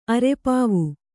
♪ arepāvu